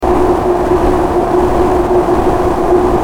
Subway Ambience 03
Subway_ambience_03.mp3